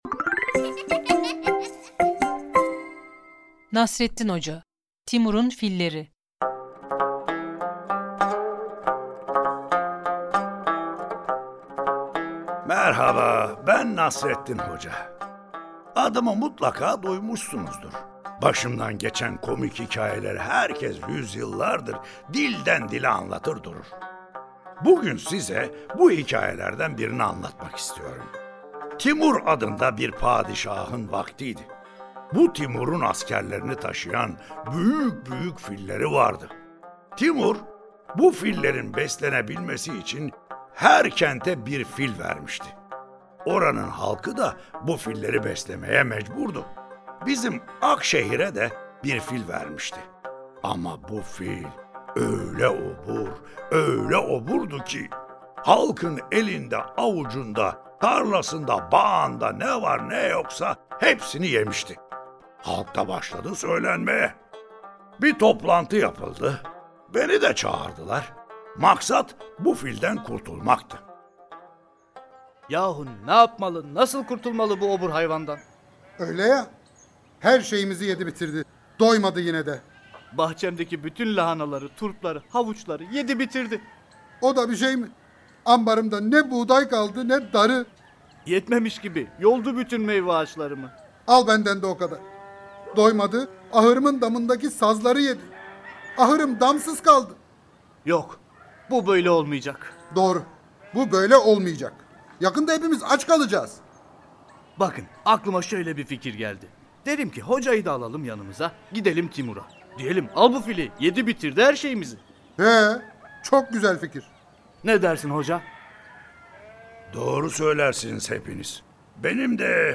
Bu Sesli Tiyatro eseri, okumalı boyama kitaplarıyla desteklenmektedir.